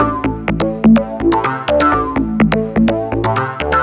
cch_bass_loop_clarke_125_Dm.wav